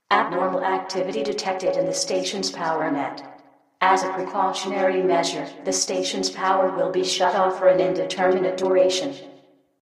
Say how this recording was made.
* Mix stereo announcements to mono